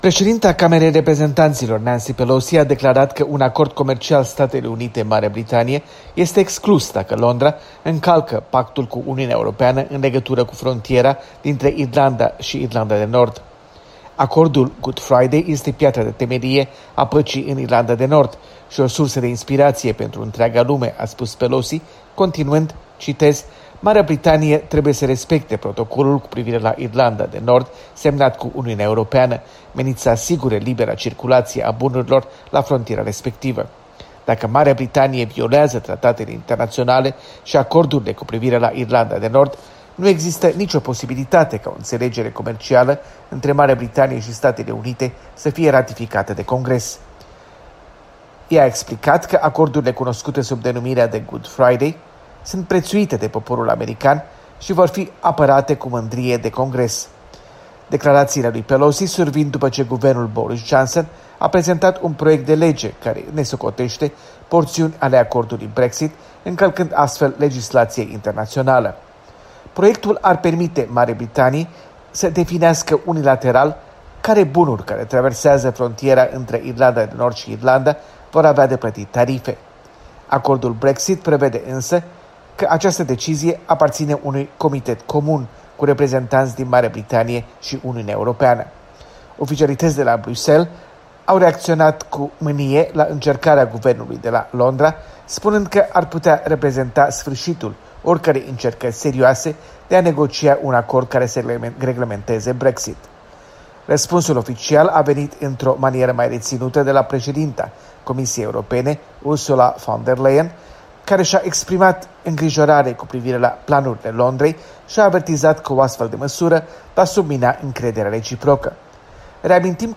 Corespondență de la Washington: Brexit